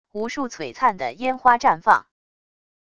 无数璀璨的烟花绽放wav音频